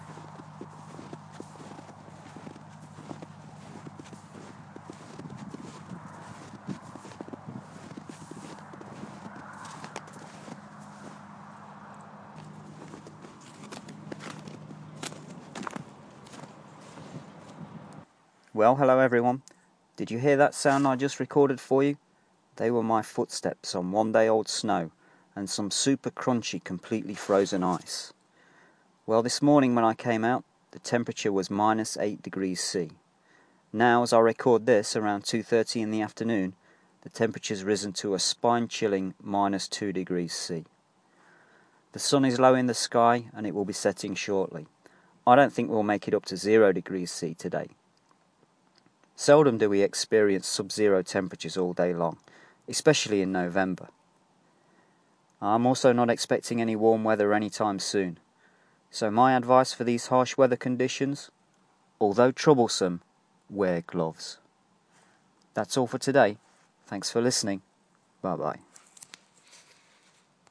Crunchy Snow Footsteps